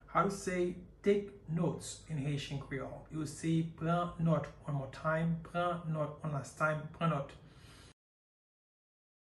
Pronunciation:
Take-notes-in-Haitian-Creole-Pran-not-pronunciation-by-a-Haitian-teacher.mp3